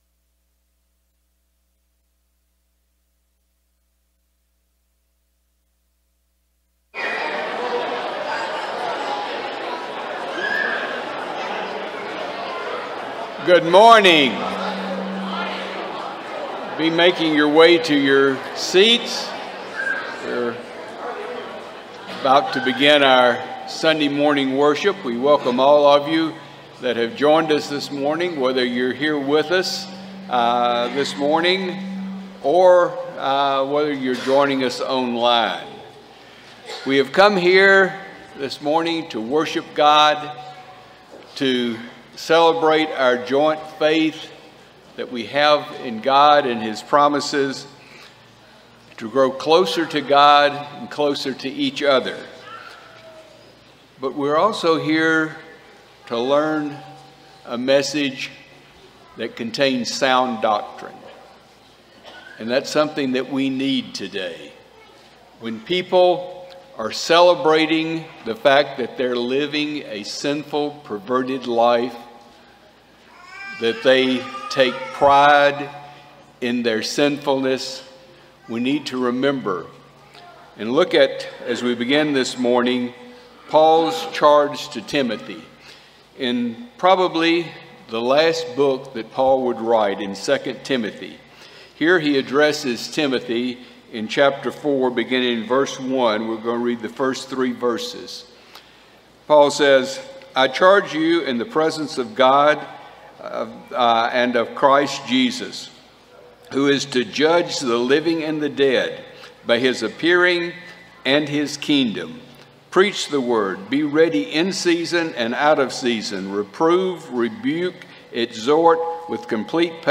Ephesians 5:25, English Standard Version Series: Sunday AM Service